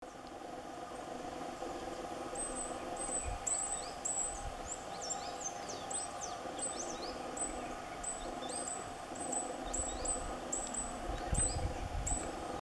Beija-flor-verde-e-branco (Elliotomyia chionogaster)
Nome em Inglês: White-bellied Hummingbird
Localidade ou área protegida: Parque Nacional Calilegua
Condição: Selvagem
Certeza: Gravado Vocal